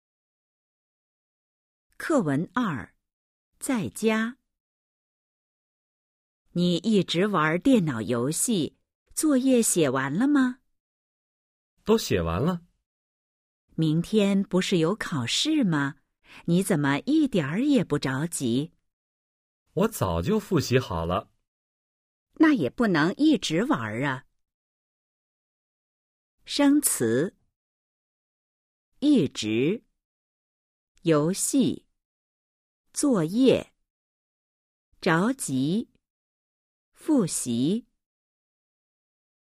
Bài hội thoại 2: 🔊 在家 /Zàijiā/ Ở nhà  💿 01-02